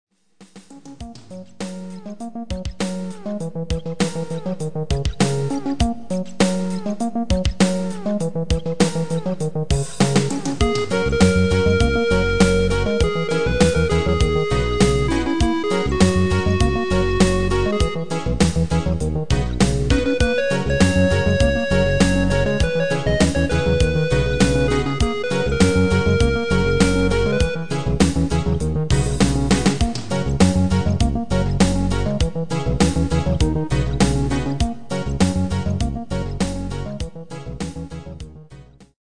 Midi file
Genre: Reggae / Latin / Salsa
Demo's played are recordings from our digital arrangements.